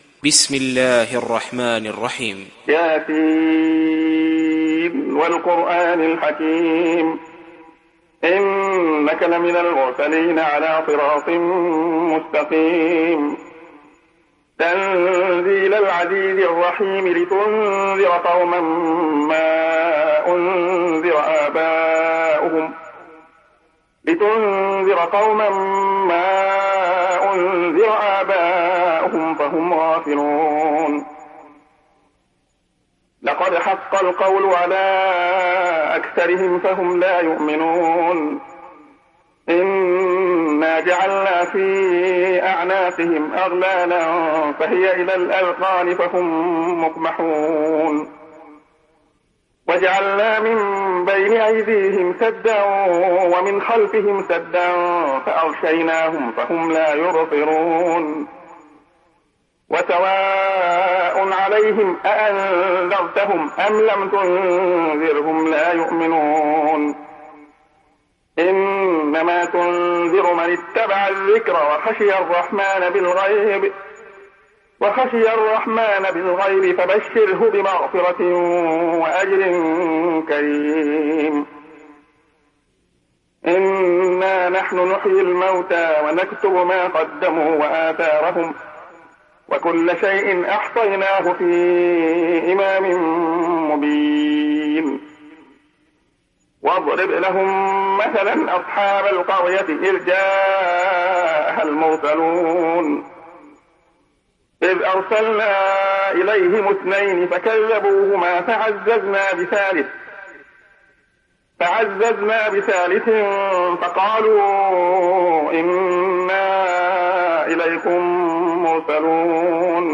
Yasin Suresi İndir mp3 Abdullah Khayyat Riwayat Hafs an Asim, Kurani indirin ve mp3 tam doğrudan bağlantılar dinle